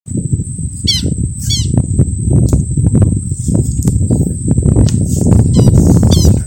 Tero Común (Vanellus chilensis)
Nombre en inglés: Southern Lapwing
Localidad o área protegida: Reserva Natural y Dique La Angostura
Condición: Silvestre
Certeza: Vocalización Grabada
tero.mp3